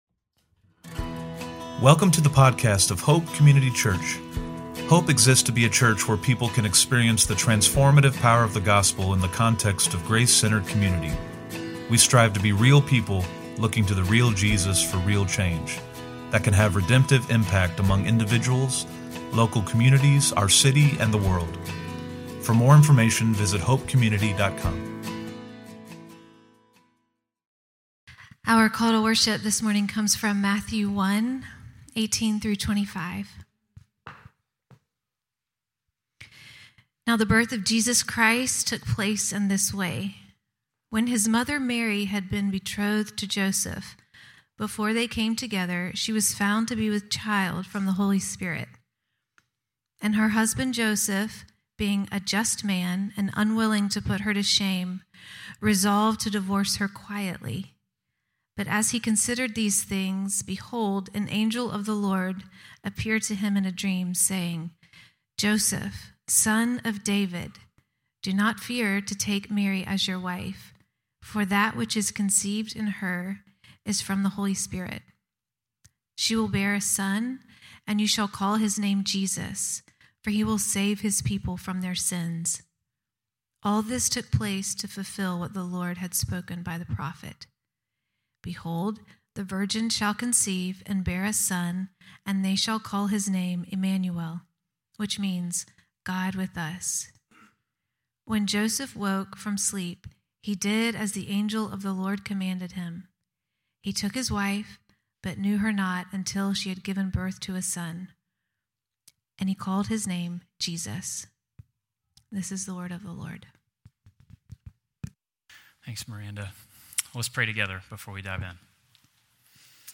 SE-Sermon-12.7.25.mp3